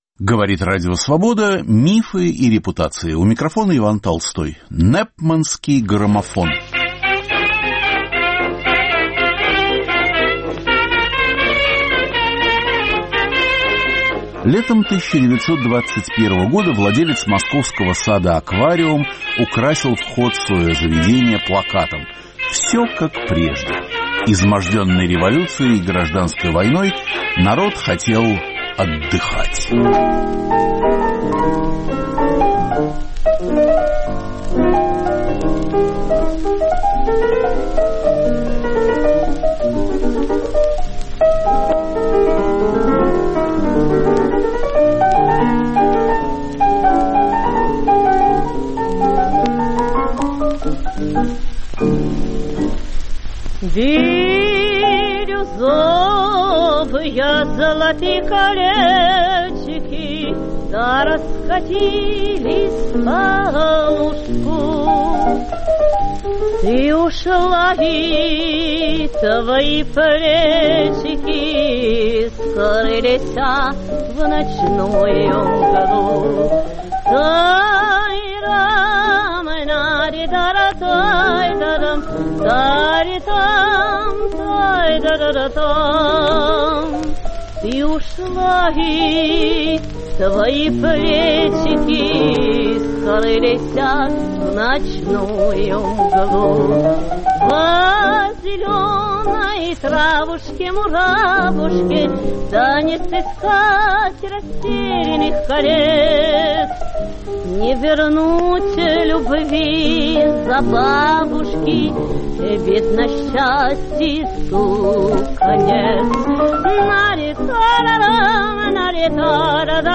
Каждый выпуск программы сопровождают новые музыкальные записи.